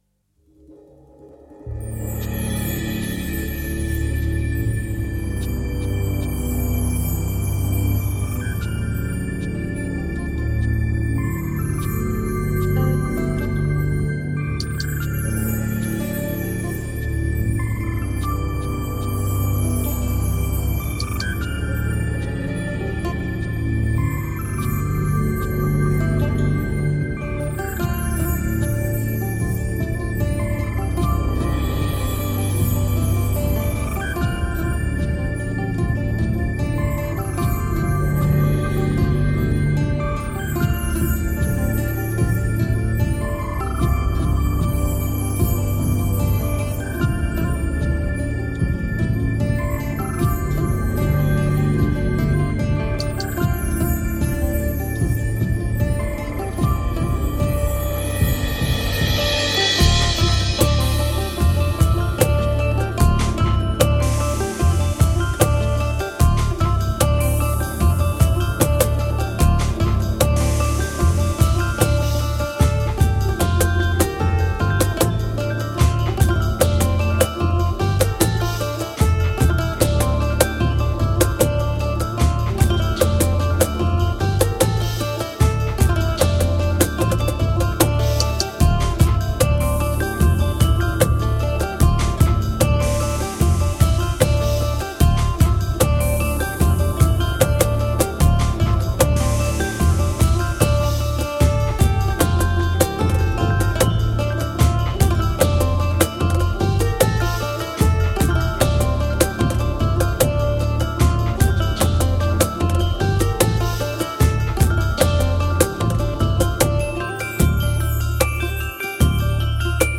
Žánr: World music/Ethno/Folk
bal folk nuevo. tramiditional dance music.
laridée 8tps